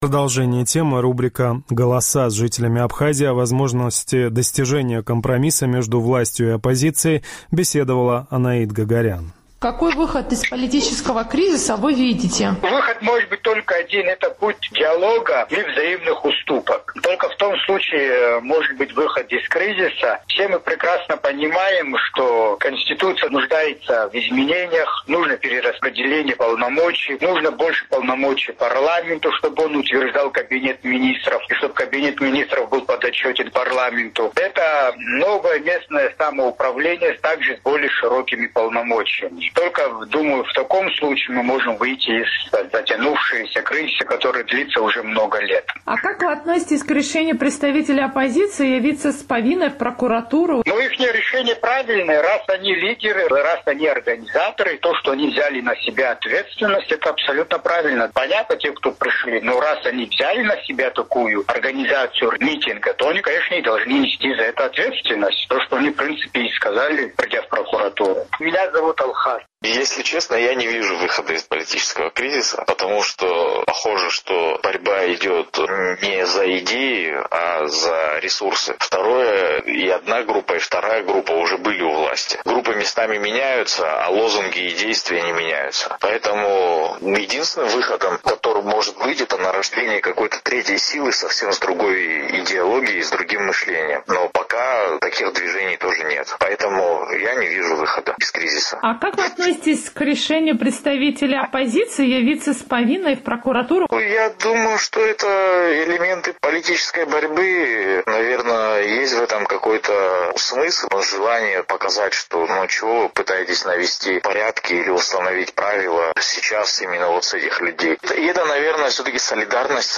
Политический кризис в Абхазии продолжается, стороны так и не пришли к компромиссу. «Эхо Кавказа» узнавало, какой выход из сложившейся ситуации видят жители республики.